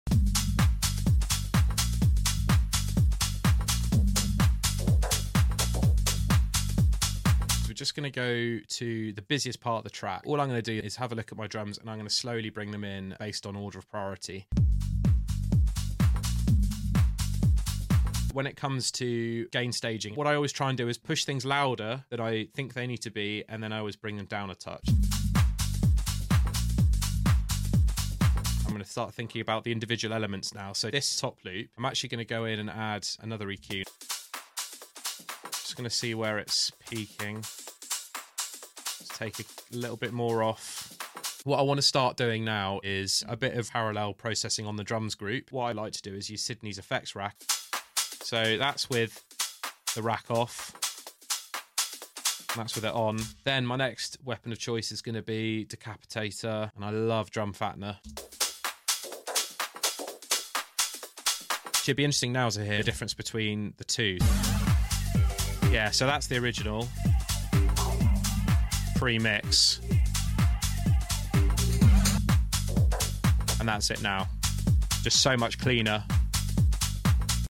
Struggling to get your drums sounding punchy? Drum mixing tutorial is on the Patreon now!